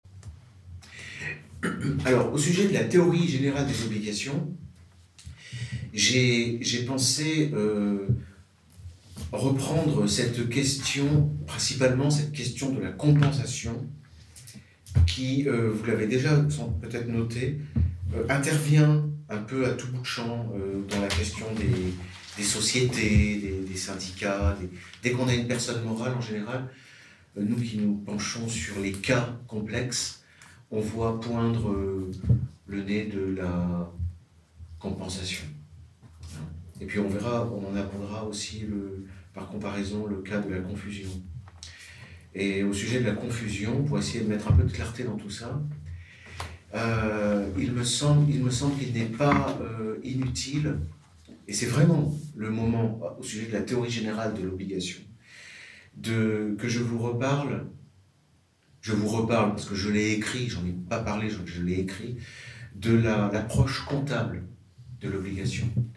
Le séminaire « la compensation » dure une heure, c’est le live d’un cours de droit que j’ai délivré dans le cadre des Formations d’Egalité et Réconciliation.